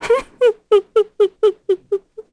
Seria-Vox_Sad_kr.wav